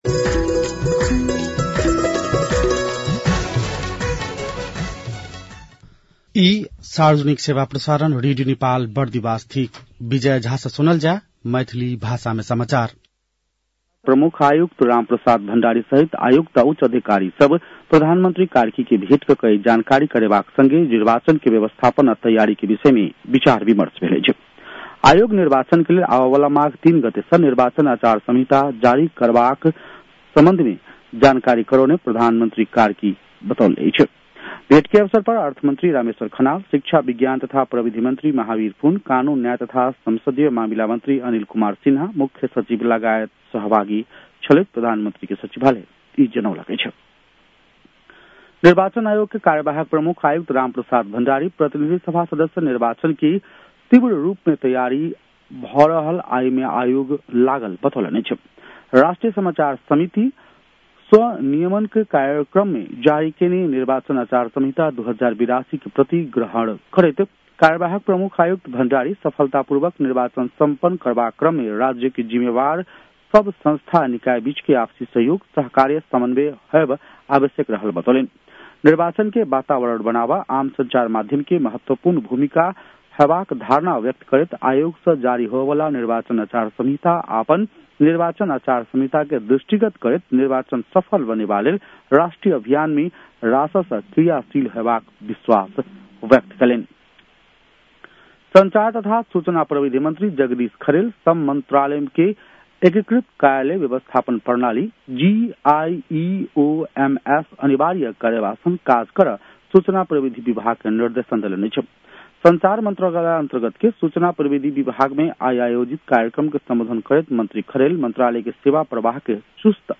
मैथिली भाषामा समाचार : २५ पुष , २०८२